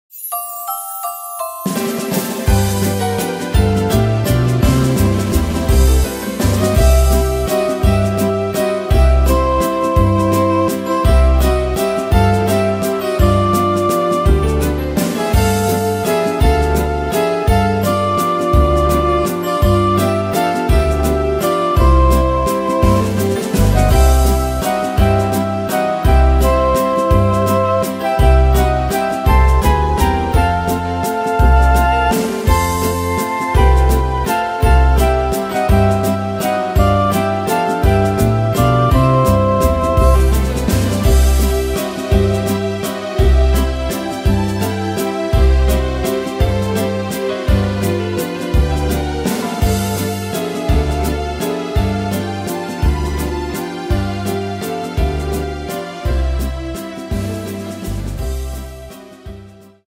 Tempo: 168 / Tonart: C-Dur